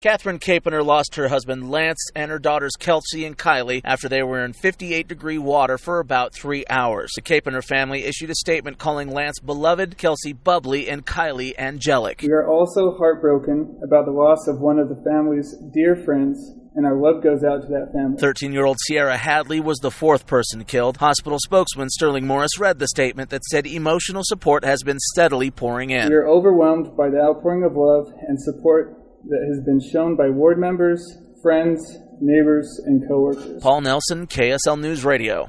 A statement from one of the survivors of a deadly boating accident on Bear Lake